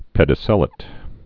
(pĕdĭ-sĕlĭt, -āt)